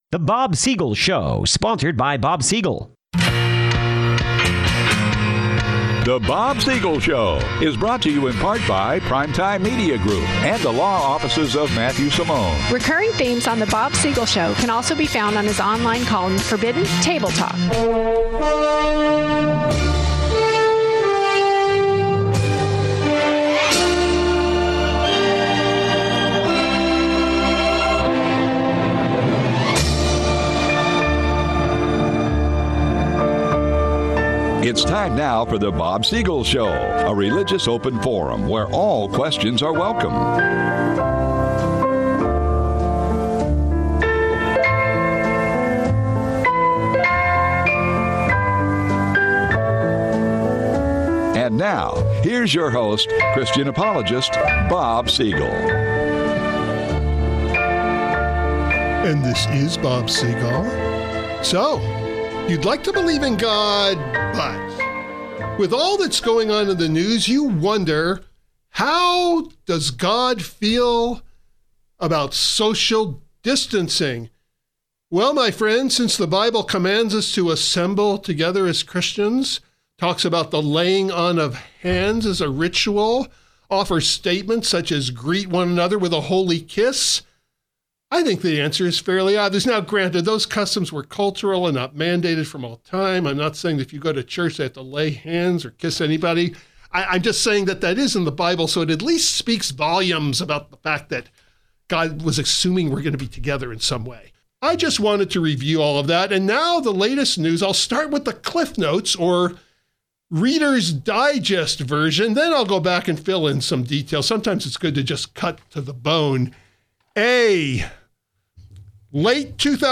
PART ONE is a combination of light-hearted satire and genuine news commentary, but both presented with the same objective; sobering spotlights on government over reach and compliance without question.